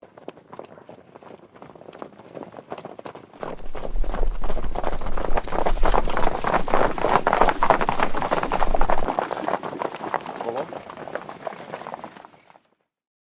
Horse Gallop on Brick
Animals
Horse Gallop on Brick is a free animals sound effect available for download in MP3 format.
yt_EykDrbhxeYg_horse_gallop_on_brick.mp3